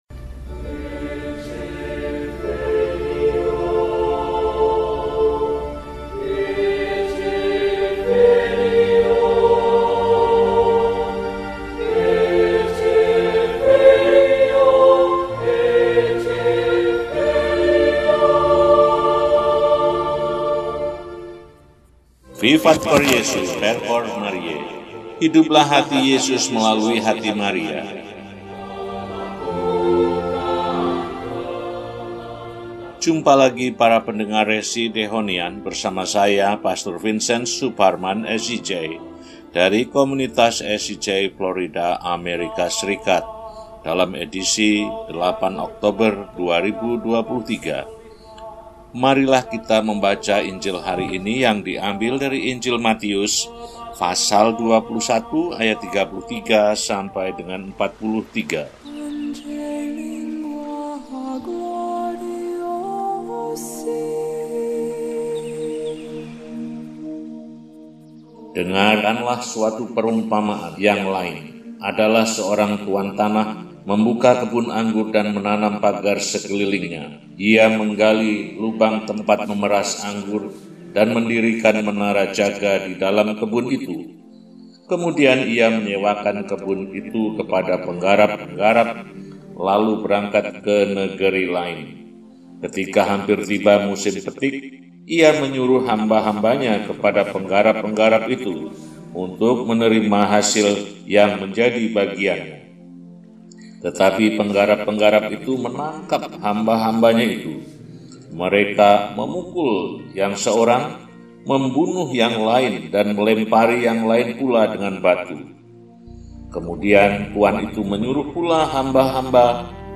Minggu, 08 Oktober 2023 – Hari Minggu Biasa XXVII – RESI (Renungan Singkat) DEHONIAN